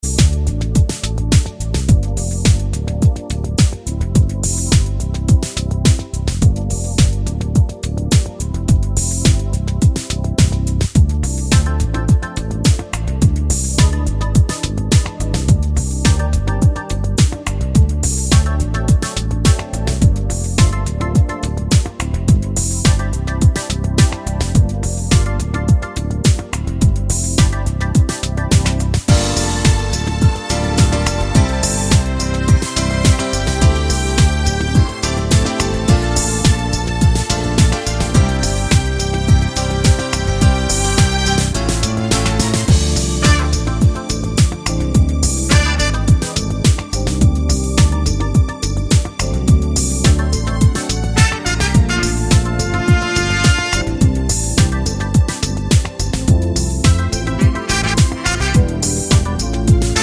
no sax solo Pop (1990s) 4:22 Buy £1.50